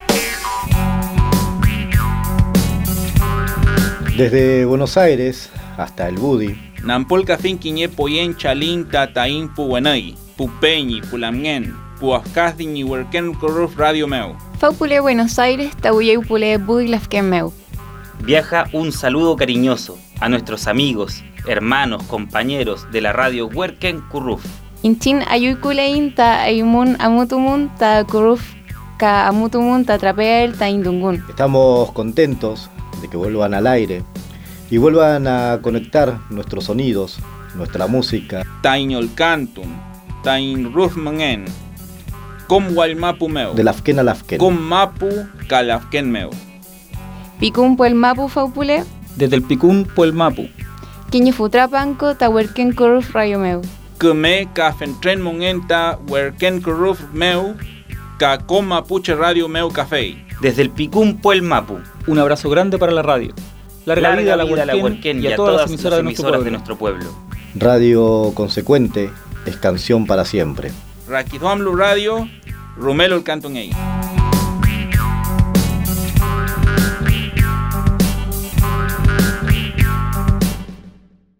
Serie documental sonora: «Vuela nuestro mapudungun» para escuchar y descargar
Los participantes del taller aprovecharon la instancia para enviar un saludo de reconocimiento a la radio Werken Kurruf del Budi, en Gulumapu.